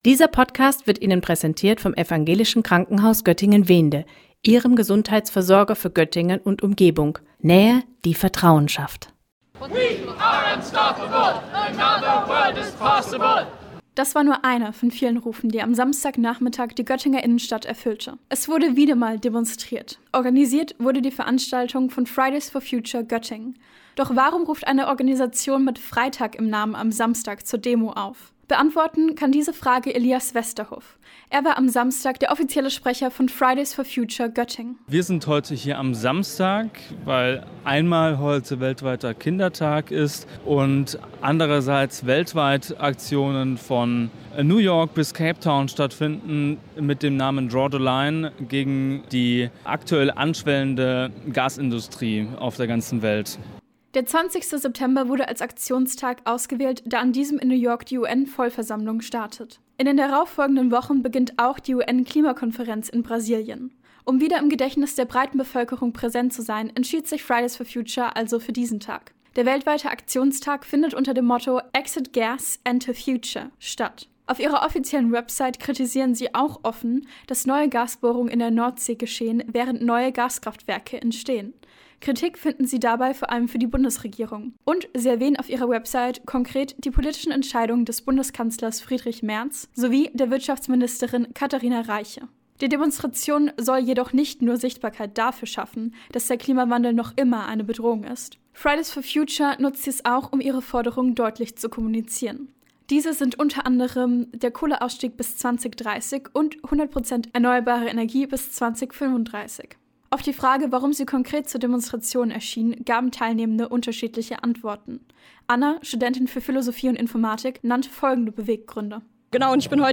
Am Samstag, dem 20. September wurde wiedermal in Göttingen demonstriert. Ab 13 Uhr versammelten sich hunderte Menschen in der Göttinger Innenstadt, um für mehr Klimaschutz zu protestieren.